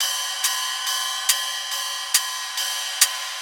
015_SD_SEISMI_LOIHI70!.wav